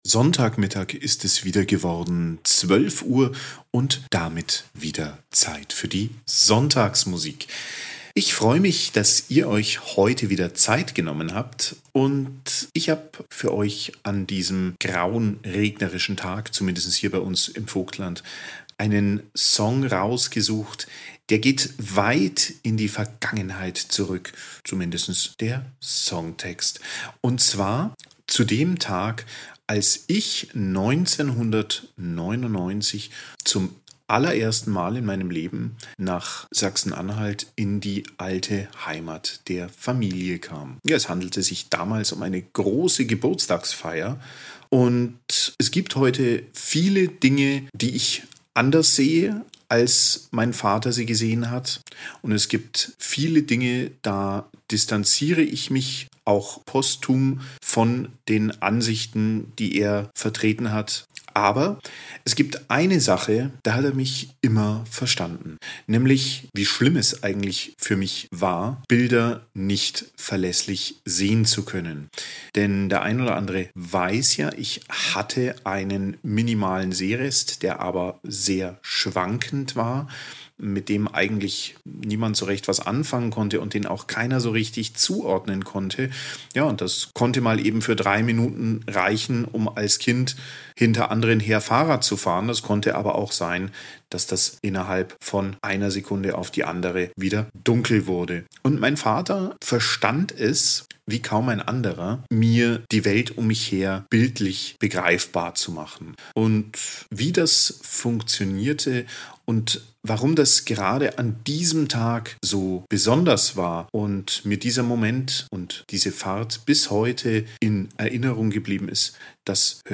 Ein Stück Sonntagsmusik